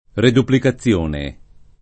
reduplicazione [ reduplika ZZL1 ne ] s. f.